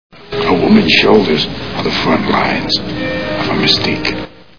Devils Advocate Movie Sound Bites